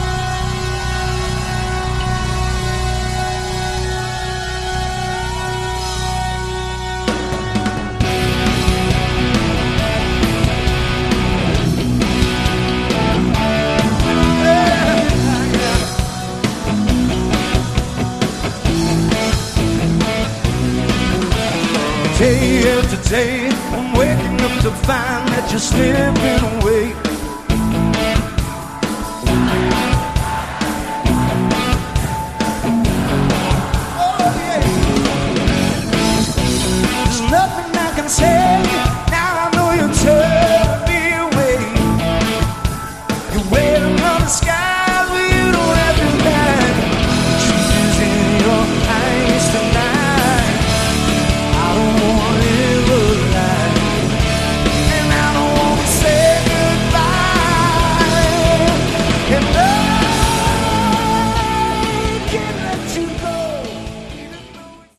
Category: Melodic Hard Rock
Vocals, Guitar
Bass
Keyboards
Drums
Live